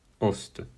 Fr-Aoste.mp3